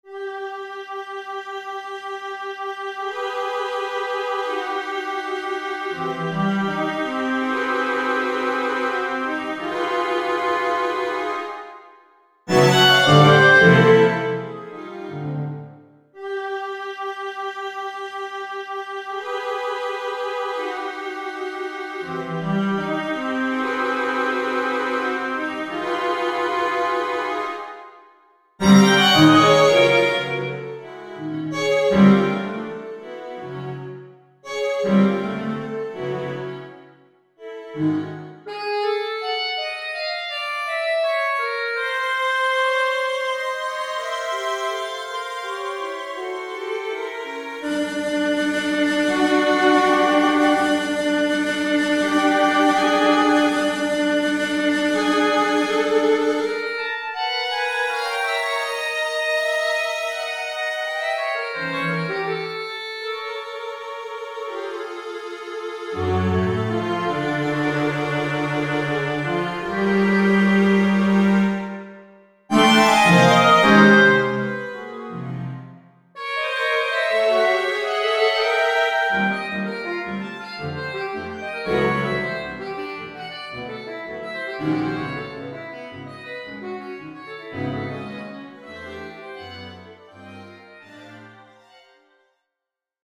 Spinal Encore  2014 – for Soprano Saxophone and String Quintet
from a live performance Feb